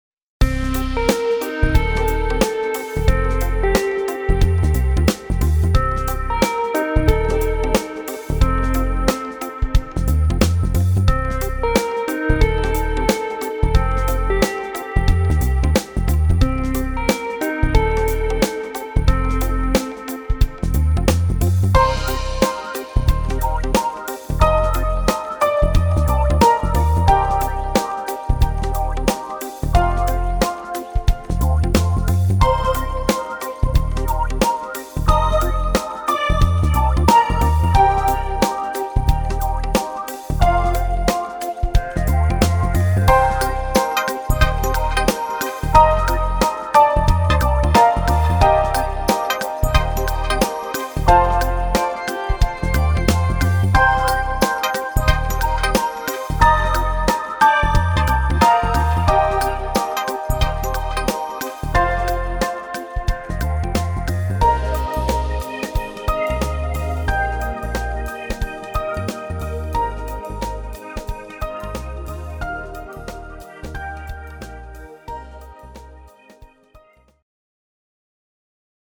Электроника какая-то.